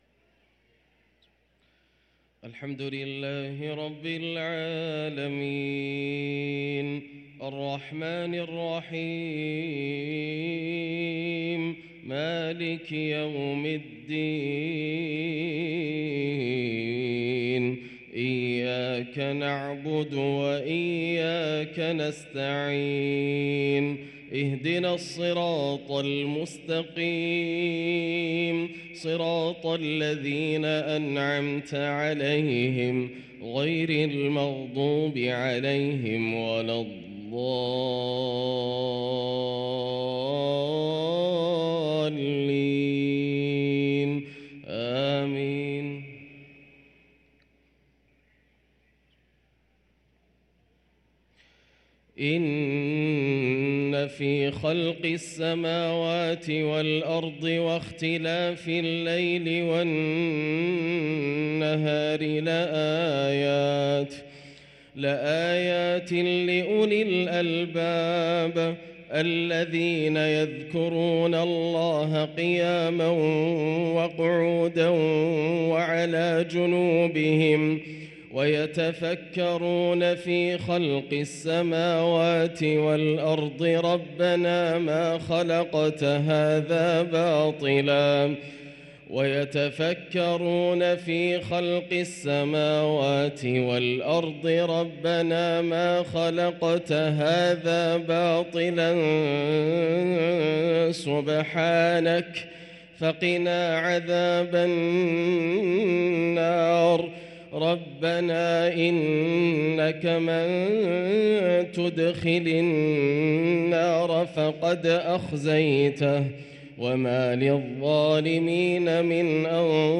صلاة العشاء للقارئ ياسر الدوسري 4 رجب 1444 هـ